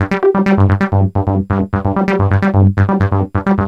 SONS ET LOOPS GRATUITS DE BASSES DANCE MUSIC 130bpm
Basse dance 3 E